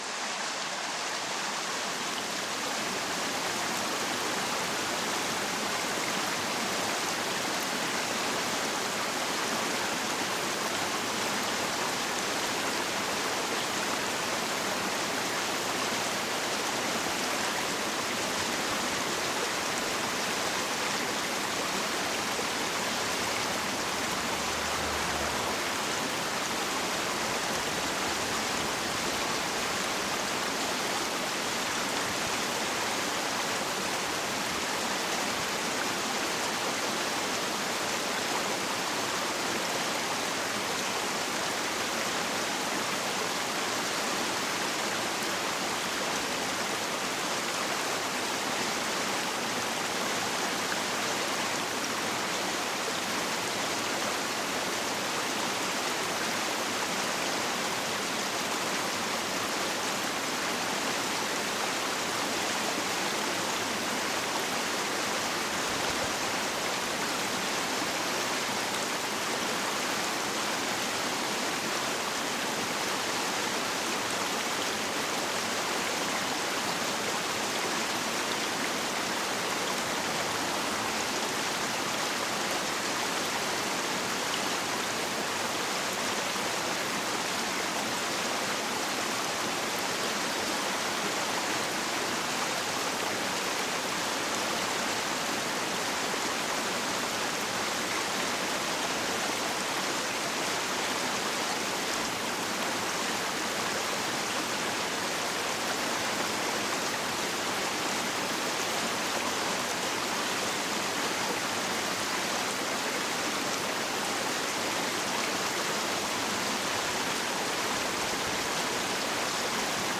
GOLDLICHT-ENTSPANNUNG: Abendflöte-Gold mit sanfter Brise